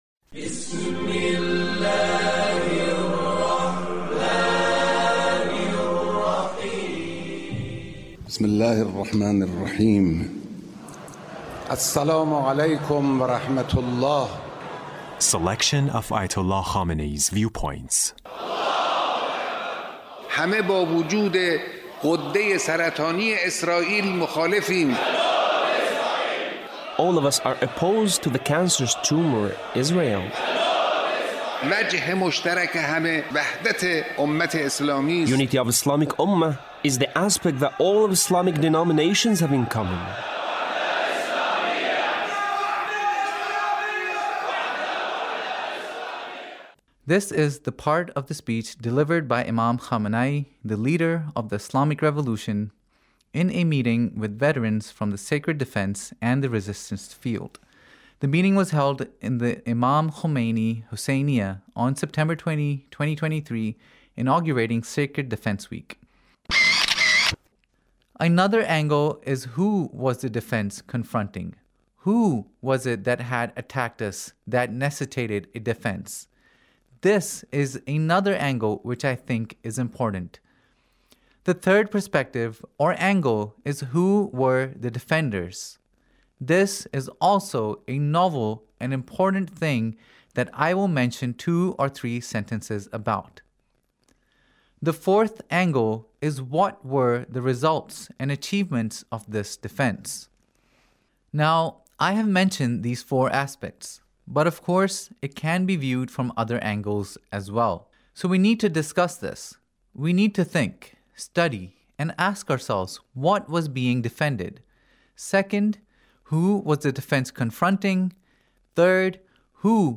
Leader's Speech (1871)
Leader's Speech about Sacred defense